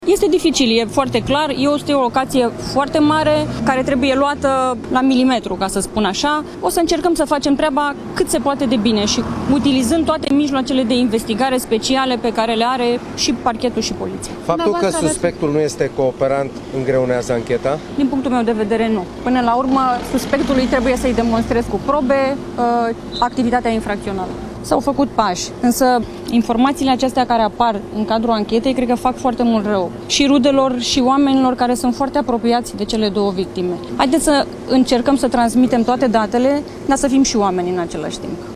Chiar in fata casei suspectului, Georgiana Hosu, procuror-șef adjunct al DIICOT, a declarat că ancheta este dificilă deoarece este vorba despre o locație foarte mare „care trebuie luată la milimetru”.